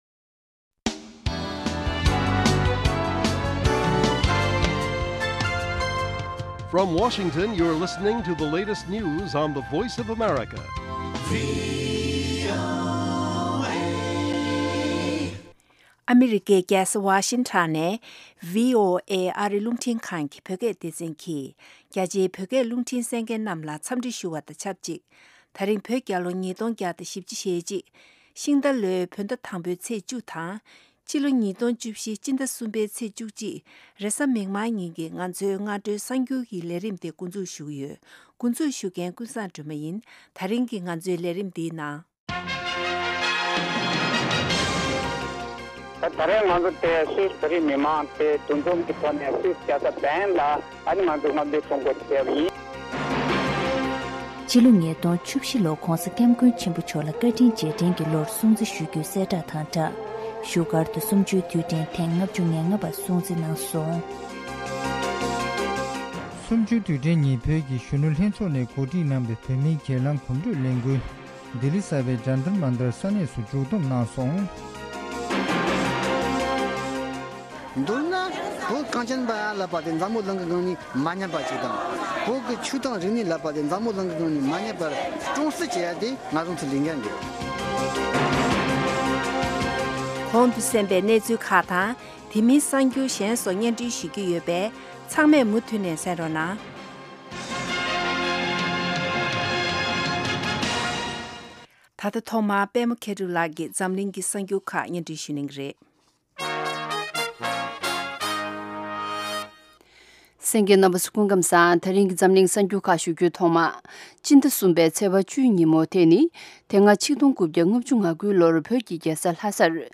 སྔ་དྲོའི་གསར་འགྱུར།